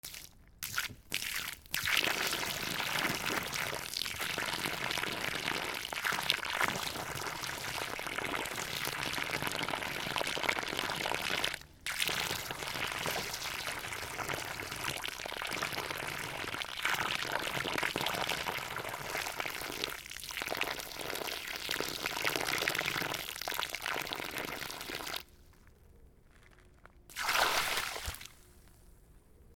/ M｜他分類 / L30 ｜水音-その他
砂場に水を落とす
ジョボボボ 特殊用途 R26XY